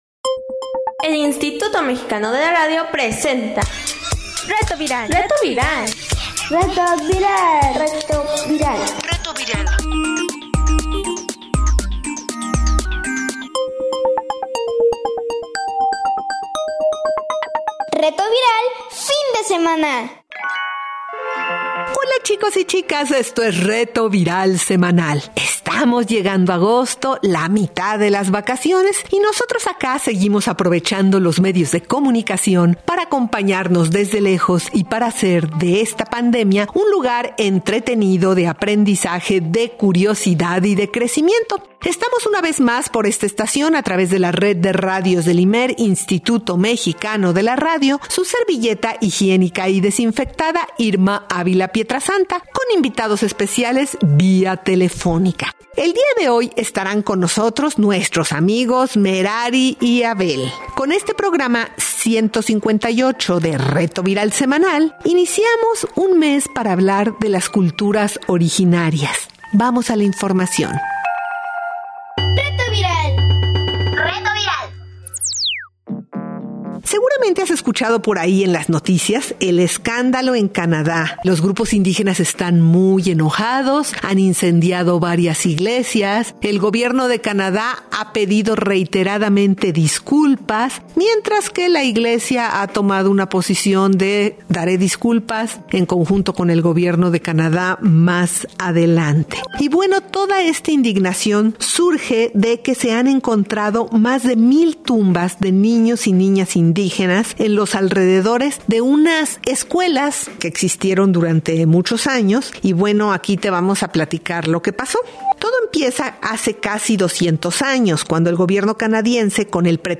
Diversidad y poder / Músico invitado: Maguaré música para niños